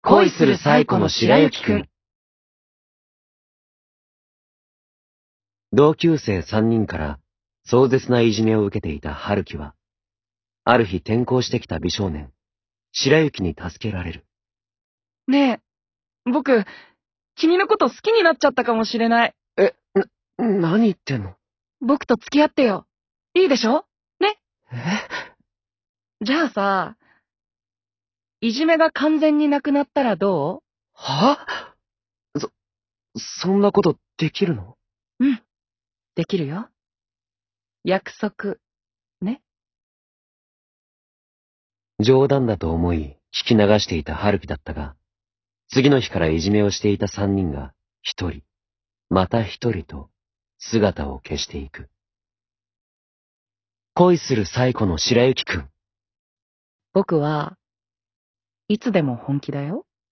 【ボイスドラマ】
ボイスドラマ「恋するサイコの白雪くん」